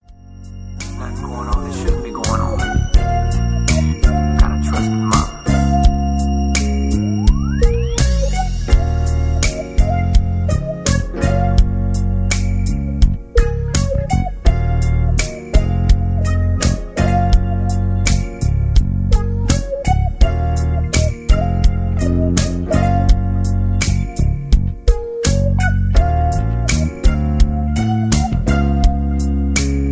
(Key-Db) Karaoke Mp3 Backing Tracks